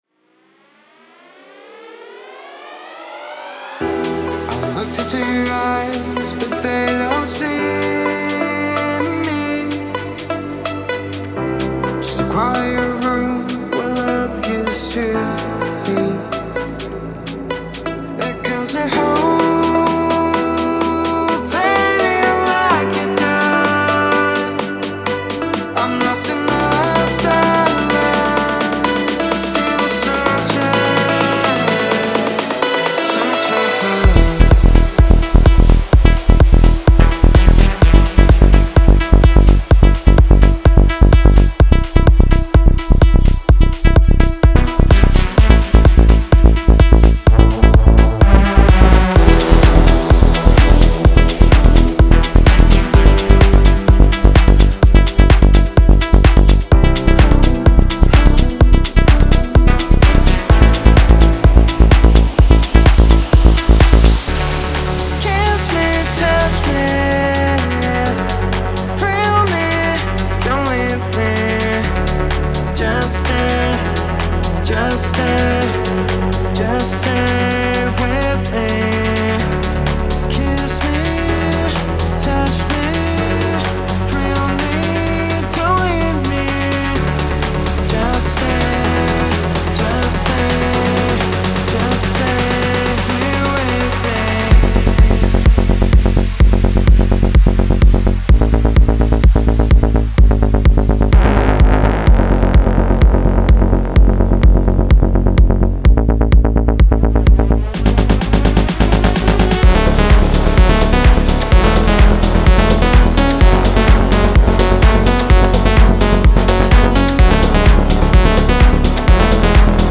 デモサウンドはコチラ↓
Genre:Melodic Techno
127 BPM
202 Wav Loops (Vocals, Basses, Synths, Drums, Fx)